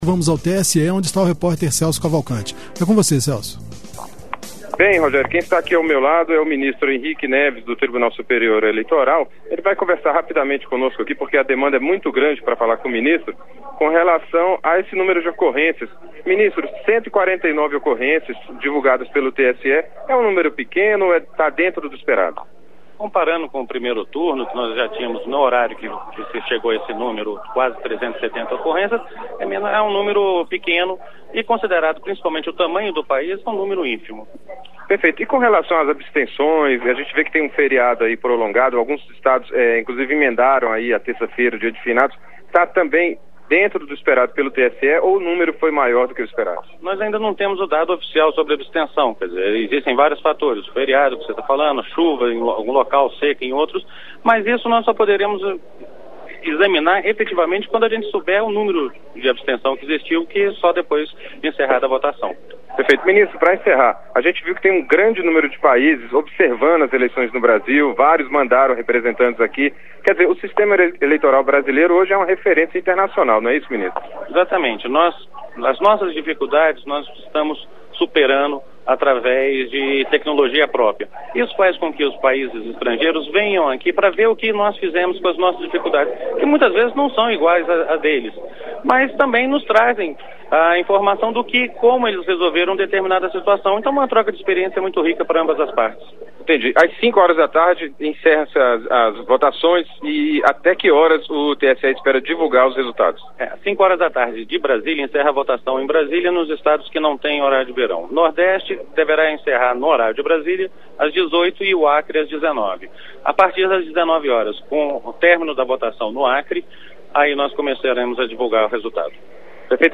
Entrevista com o ministro Henrique Neves.